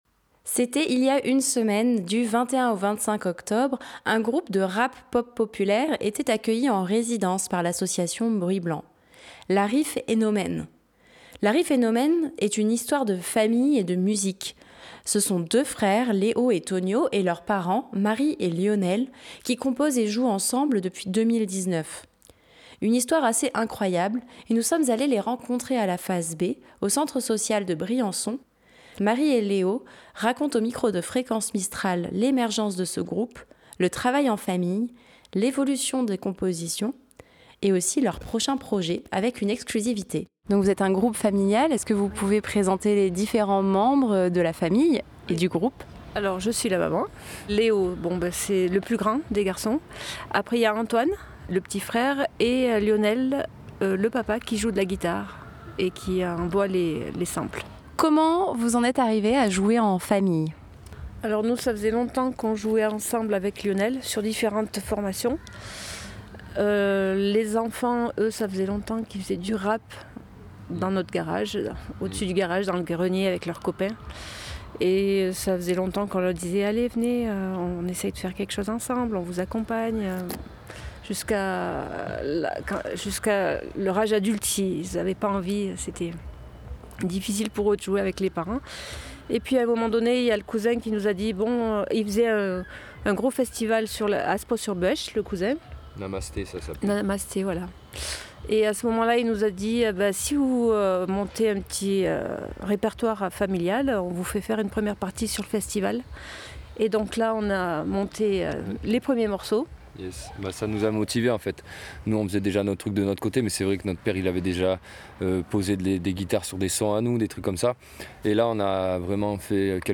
Nous sommes allés les rencontrer à la Face B, au centre social de Briançon, lors d'une répétition.